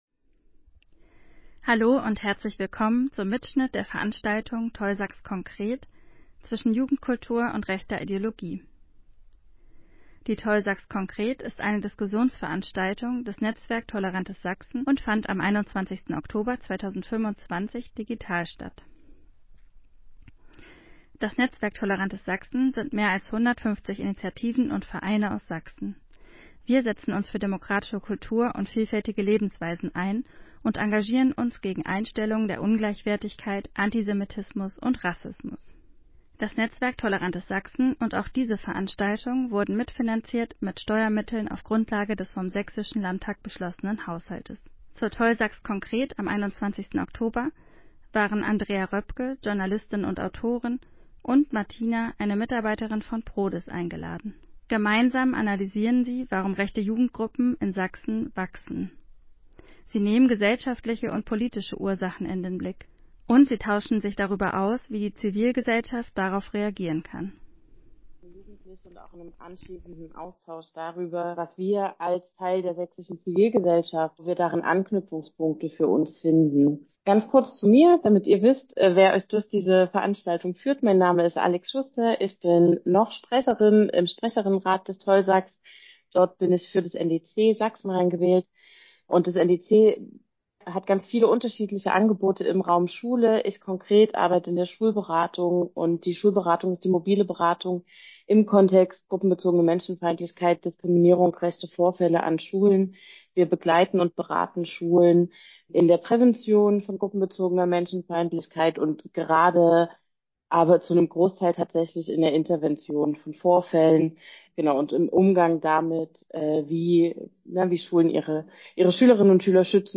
Am 21.10.2025 fand die digitale TolSax-Konkret statt. Wir analysierten, warum rechte Jugendgruppen in Sachsen wachsen – mit Blick auf gesellschaftliche und politische Ursachen.
Zwischen Jugendkultur & rechter Ideologie? – Analyse und Gespräch über rechtsextreme Jugendliche